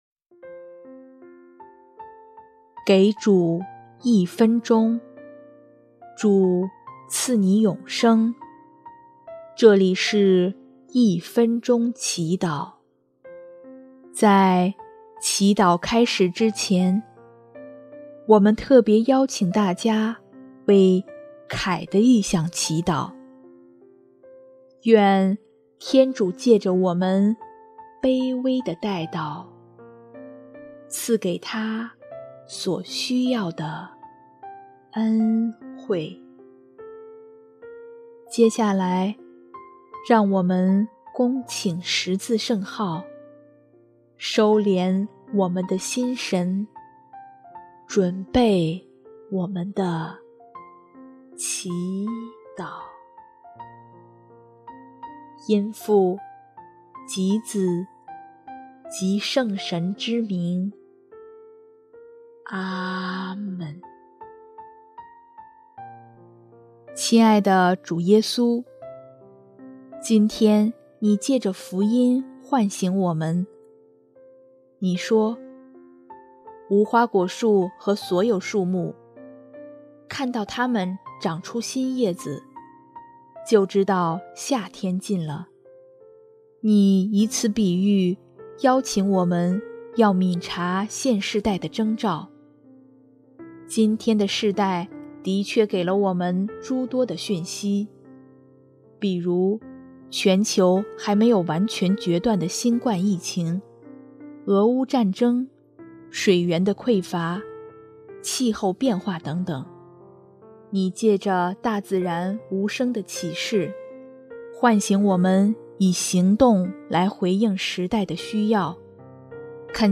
音乐：第一届华语圣歌大赛参赛歌曲